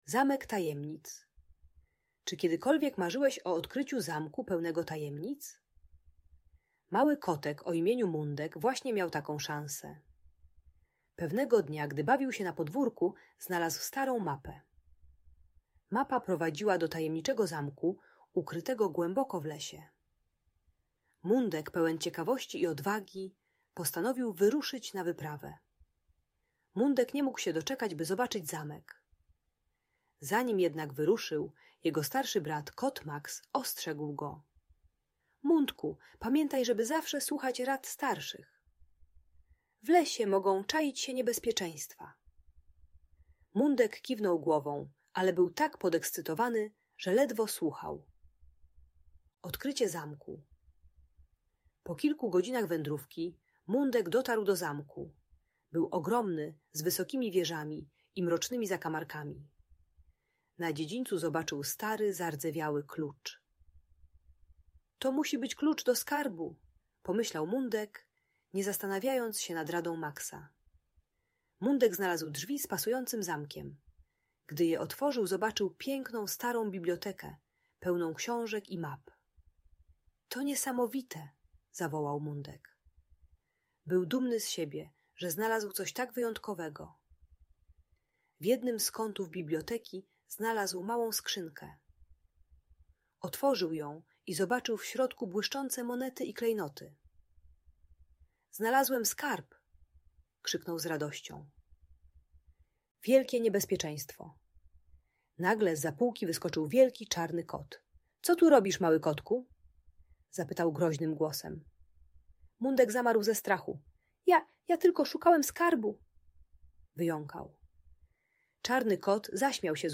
Historia kotka Mundka uczy, dlaczego warto słuchać rad starszych i jakie konsekwencje niesie nieposłuszeństwo. Audiobajka o słuchaniu rodziców i bezpieczeństwie.